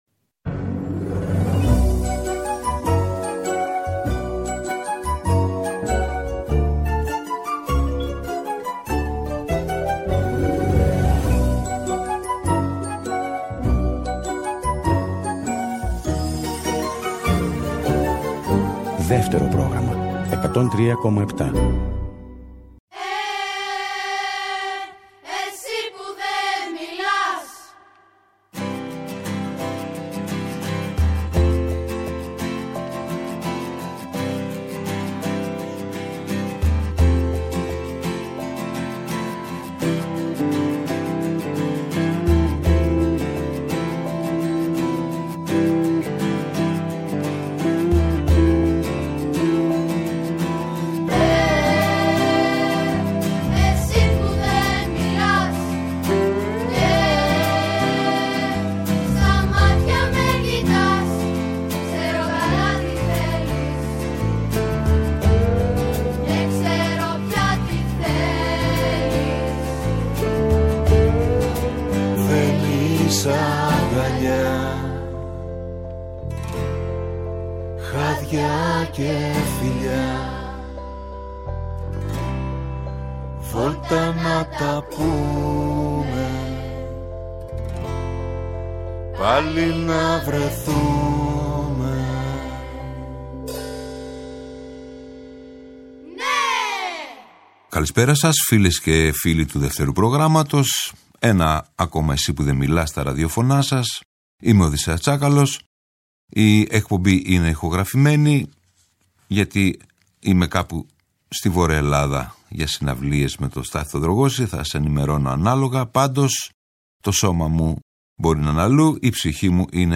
“Περασμένα, ξεχασμένα” τελευταίο μέρος με ήχο λαϊκορεμπέτικο